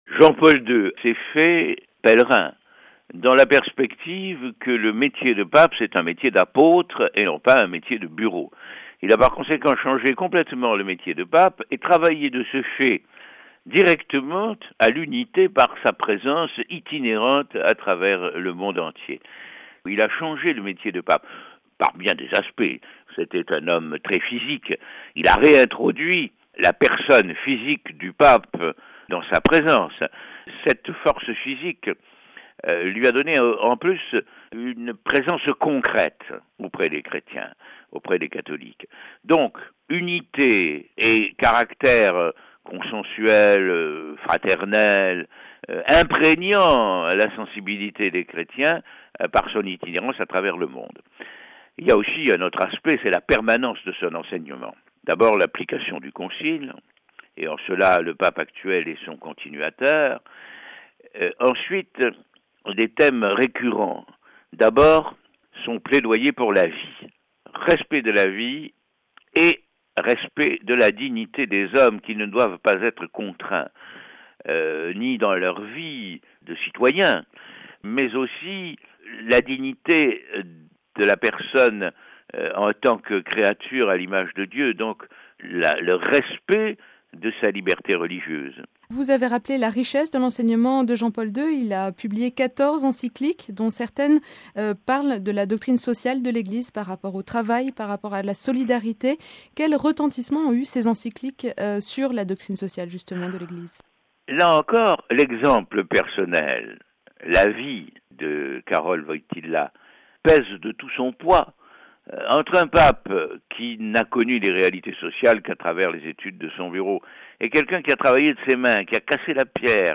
Propos recueillis